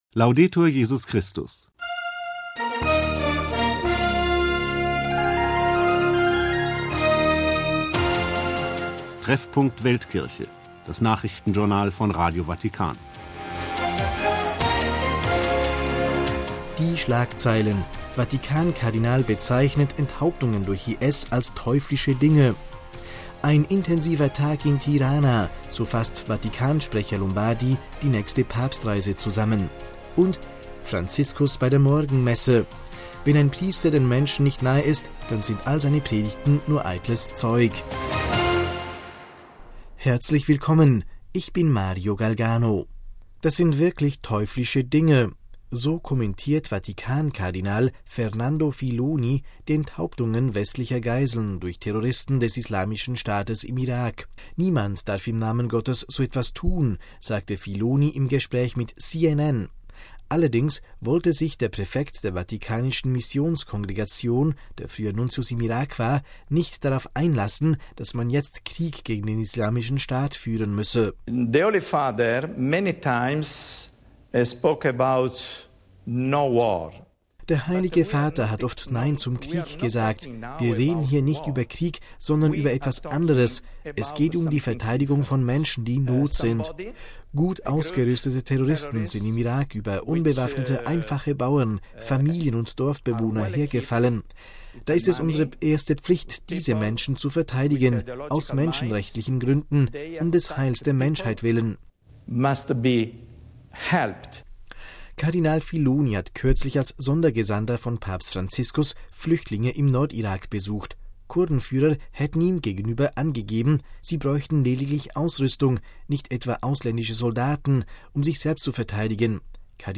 Die Nachrichtensendung vom 16. September 2014